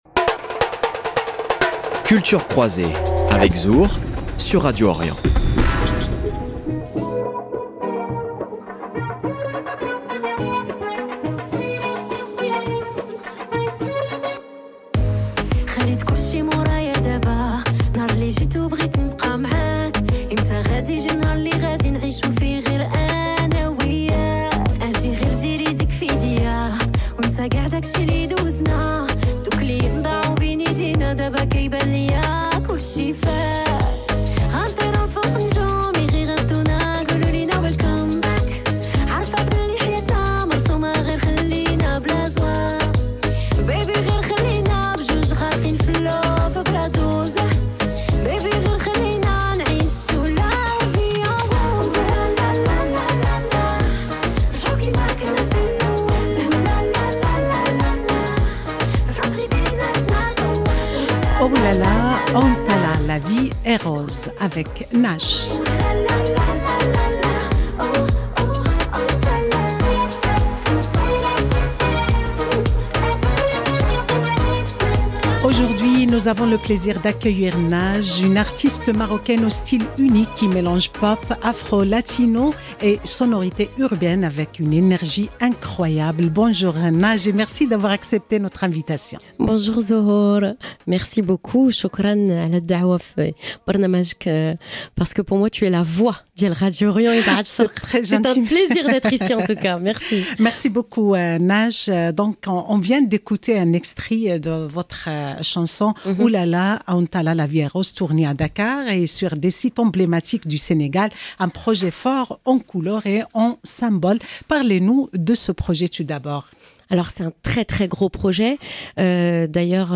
Dans cette interview exclusive, elle nous parle de ce projet haut en couleur, de ses inspirations profondes, de son énergie contagieuse, mais aussi de son engagement à travers la musique. 0:00 14 min 28 sec